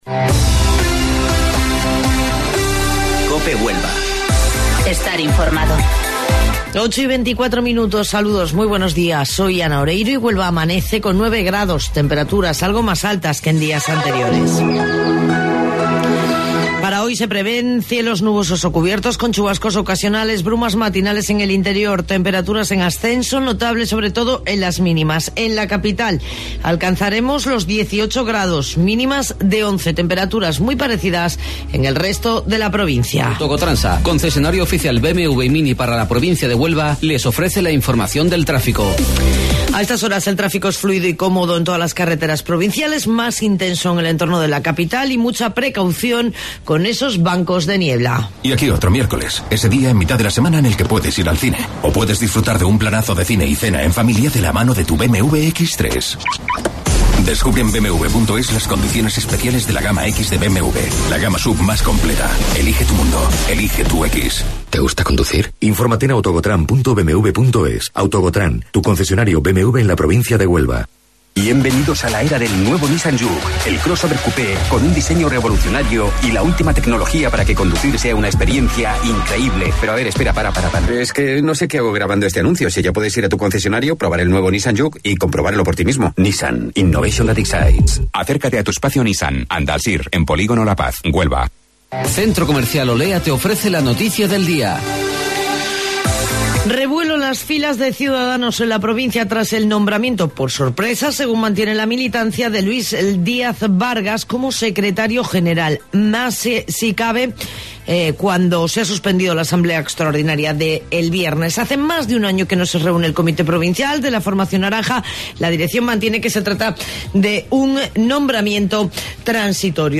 AUDIO: Informativo Local 08:25 del 15 de Enero